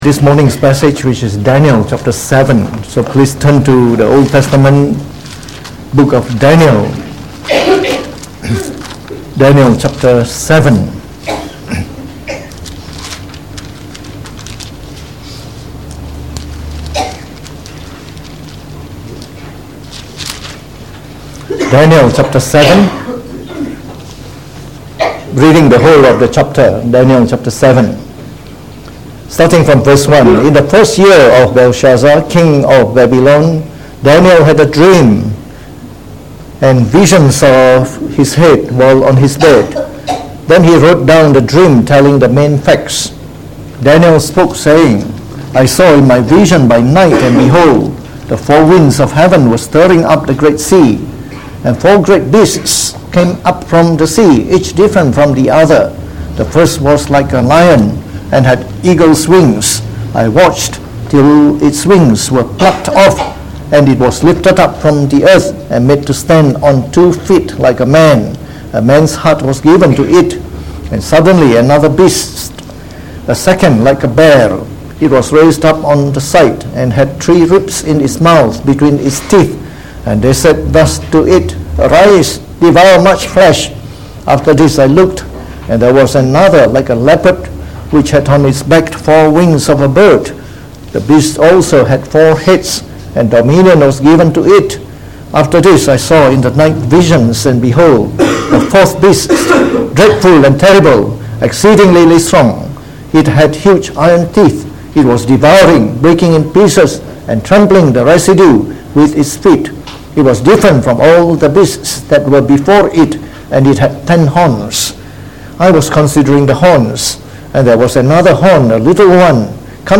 From our series on the Book of Daniel delivered in the Morning Service by Pastor.